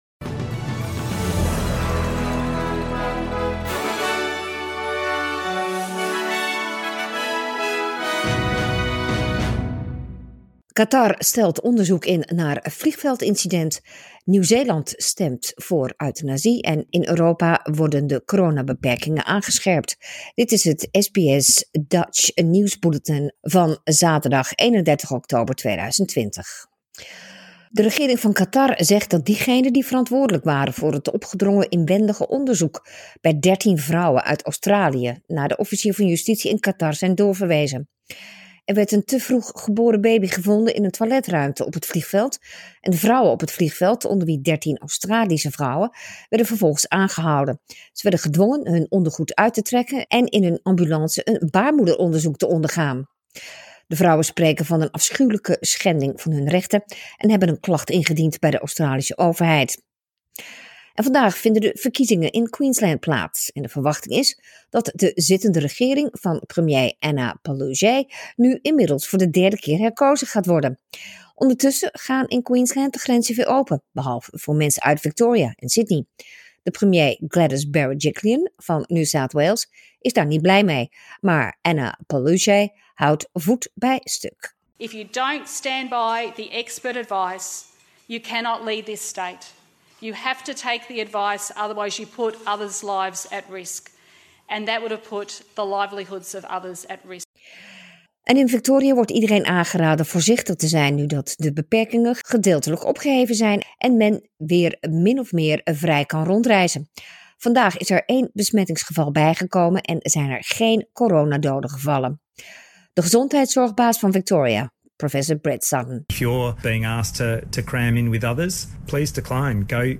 Nederlands/Australisch SBS Dutch nieuwsbulletin zaterdag 31 oktober 2020